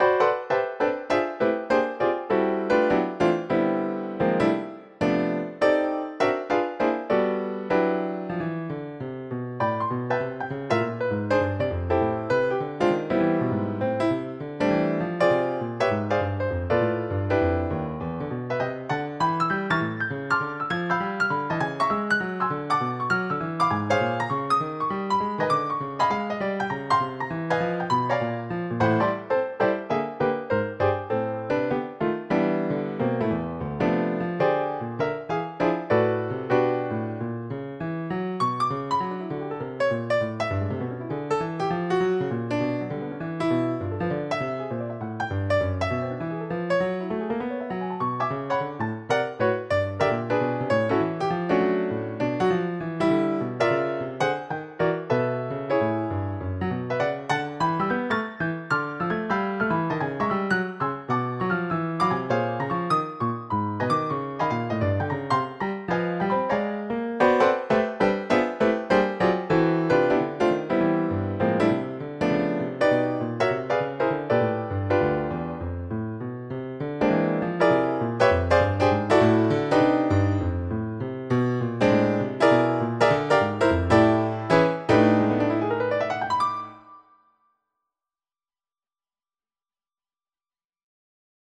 Recently I decided I wanted to get decent recordings of my thesis compositions, so I have been recording them using GarageBand.
Variation 1 is a retrograde inversion (the melody upside-down and backwards); variation 2 is a straightforward reharmonization; variation 3 is the melody in the form of a blues; variation 4 is an augmentation (one note of the melody is used in each measure of the variation's melody); variation 5 is a more interesting reharmonization.